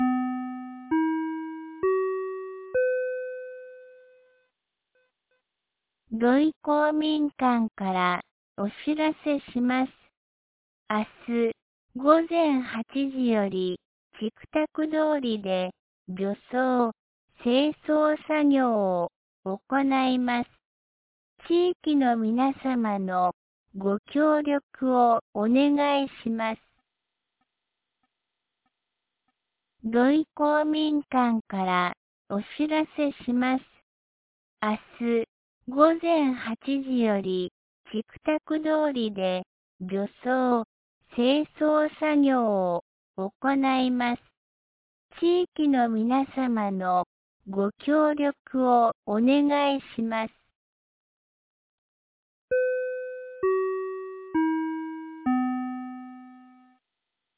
2025年12月06日 17時21分に、安芸市より土居、僧津へ放送がありました。